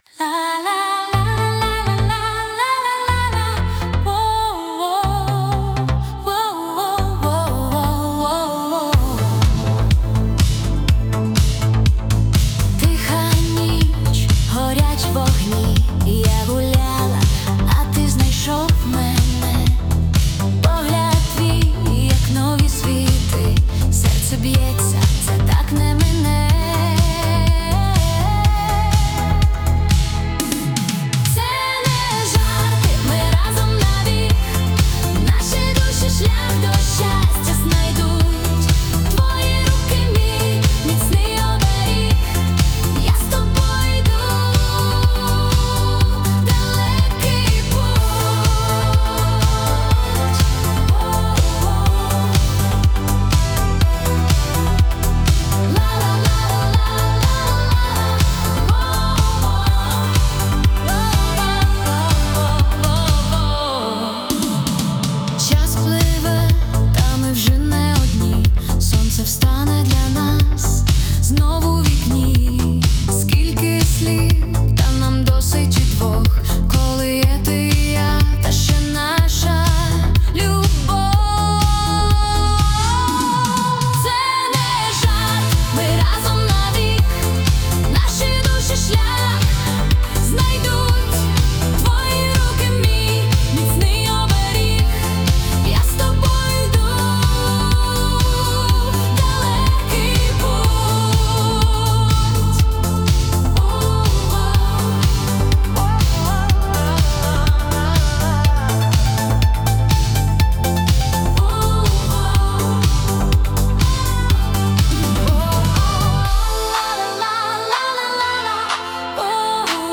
Стиль: Синт-поп